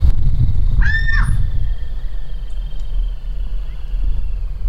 Saracuruçu (Aramides ypecaha)
Nome em Inglês: Giant Wood Rail
Fase da vida: Adulto
Detalhada localização: Laguna Hedionda
Condição: Selvagem
Certeza: Gravado Vocal